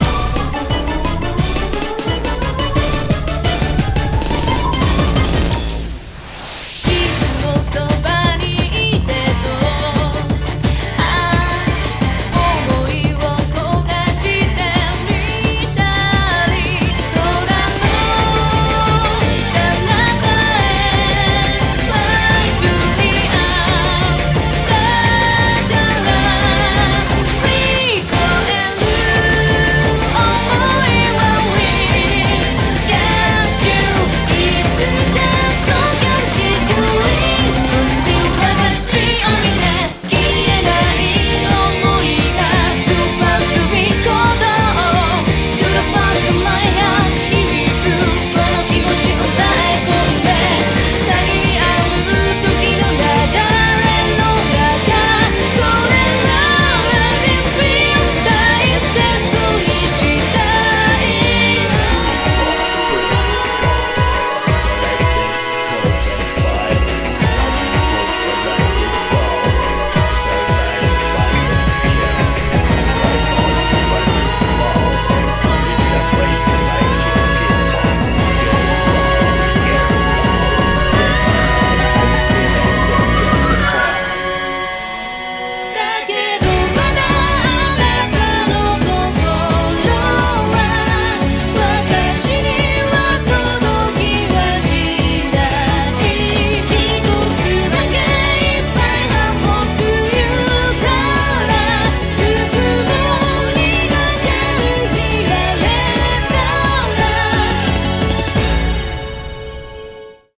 BPM175
Audio QualityLine Out